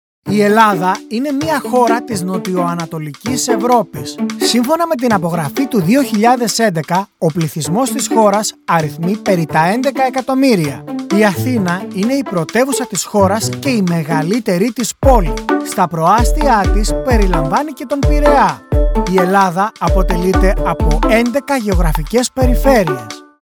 Male
Documentary
Greek, Friendly, Welcoming